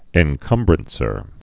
(ĕn-kŭmbrən-sər)